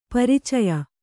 ♪ paricaya